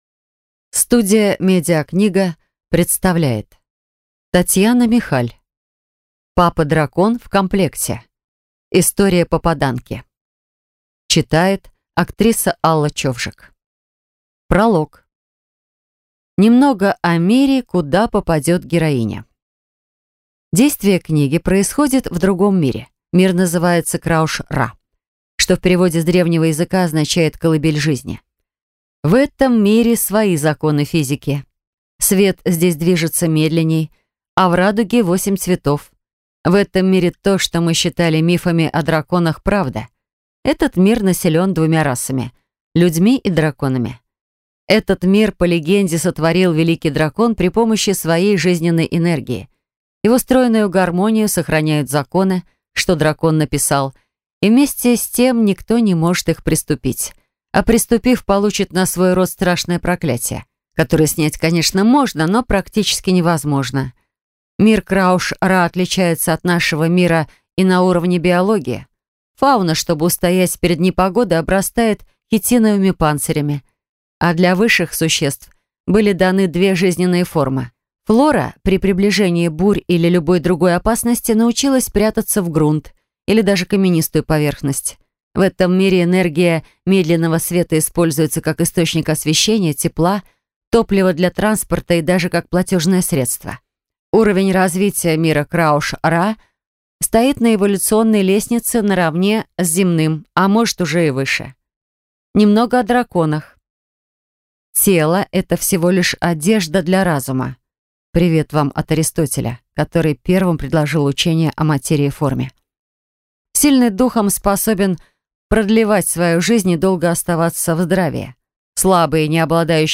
Аудиокнига Папа-Дракон в комплекте. История попаданки | Библиотека аудиокниг